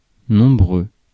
Ääntäminen
UK UK : IPA : /lɑːdʒ/ US : IPA : /lɑɹdʒ/